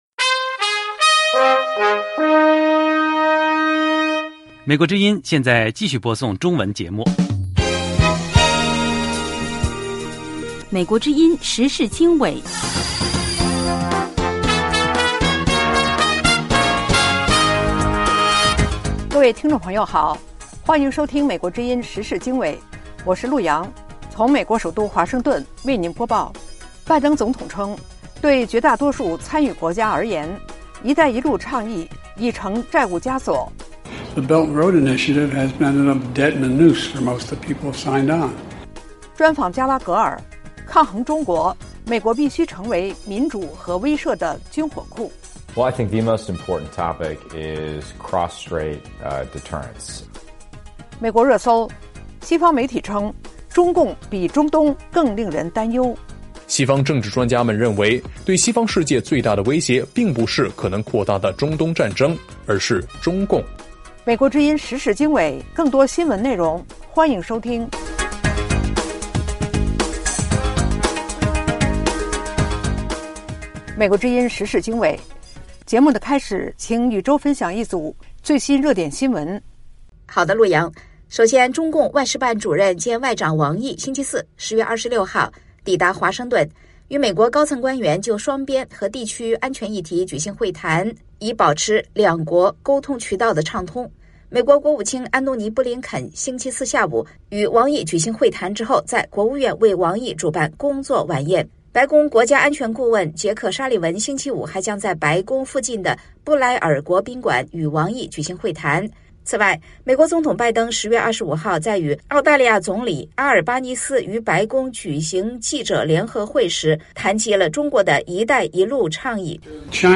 时事经纬(2023年10月27日) - 拜登总统称，对绝大多数参与国家而言，一带一路倡议已成债务枷锁; 专访加拉格尔：抗衡中国，美国必须成为民主和威慑的军火库 ; 美国热搜：西方媒体称，中共比中东更令人担忧